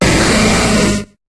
Cri de Smogogo dans Pokémon HOME.